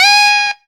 HI WOBBLE.wav